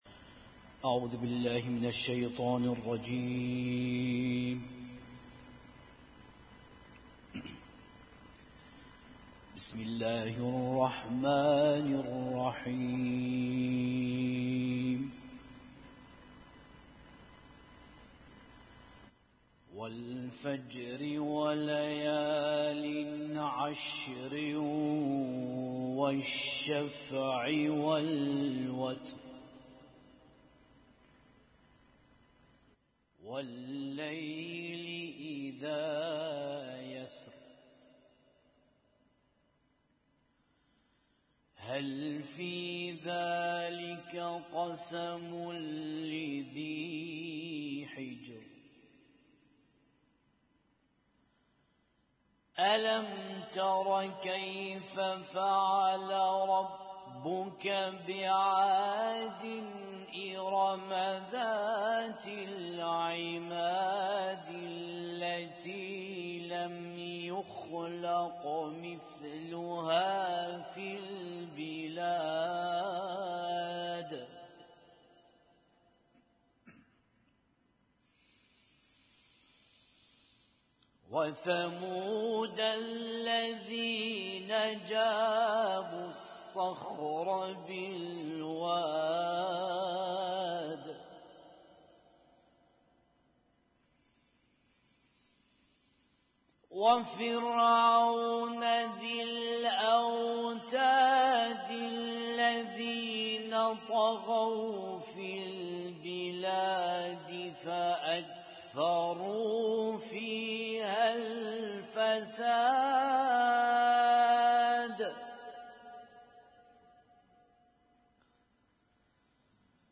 Husainyt Alnoor Rumaithiya Kuwait
اسم التصنيف: المـكتبة الصــوتيه >> القرآن الكريم >> القرآن الكريم - القراءات المتنوعة